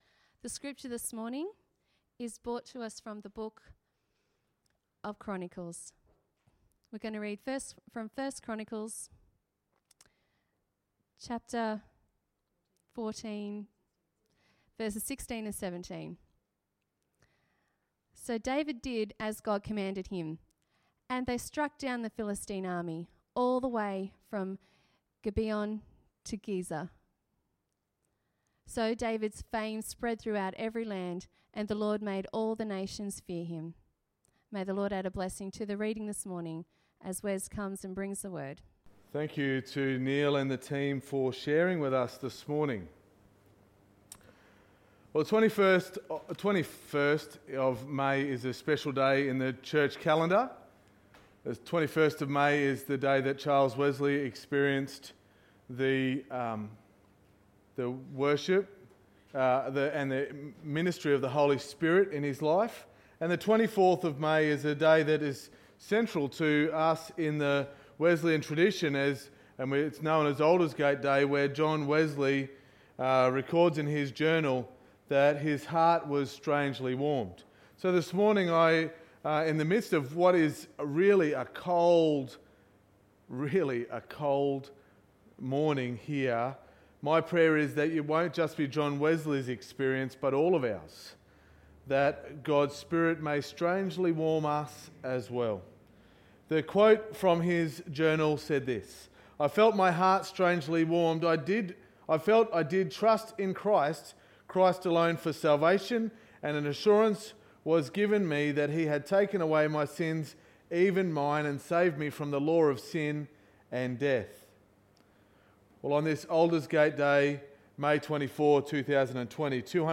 Sermon 24.5.2020